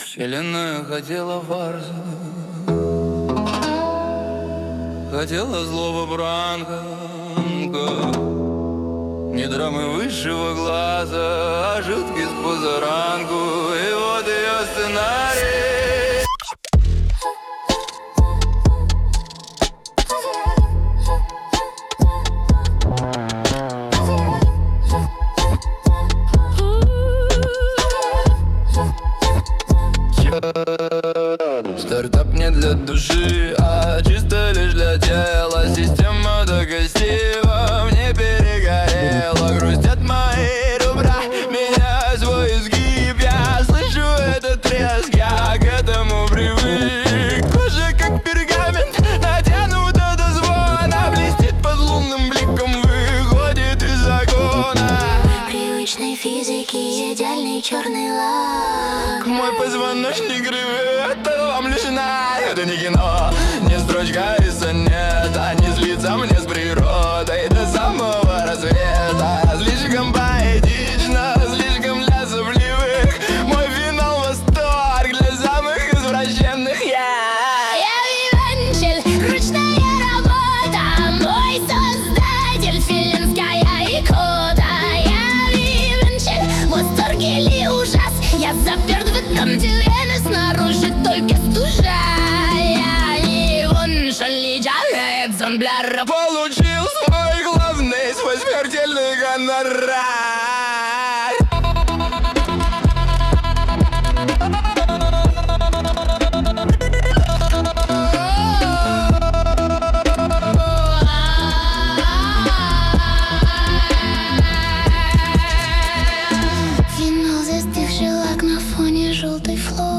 виолончель
violoncheljq.mp3